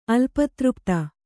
♪ alpatřpta